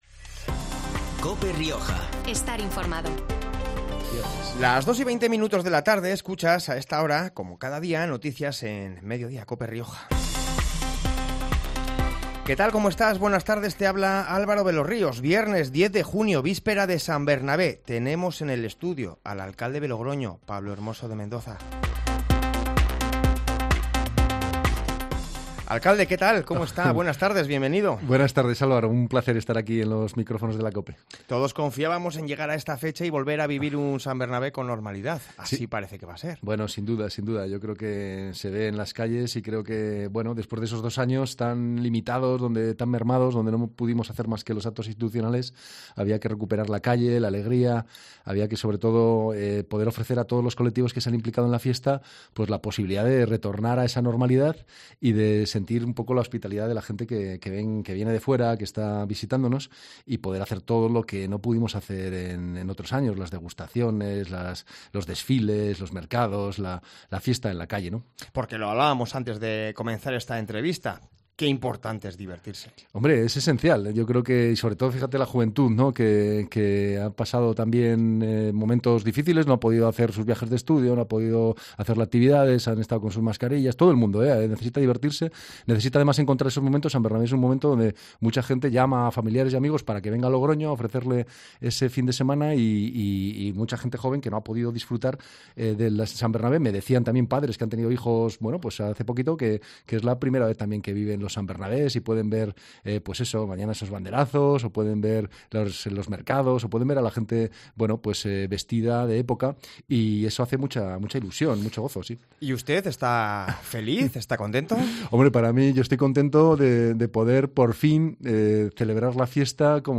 San Bernabé 2022: Escucha la entrevista a Pablo Hermoso de Mendoza, Alcalde de Logroño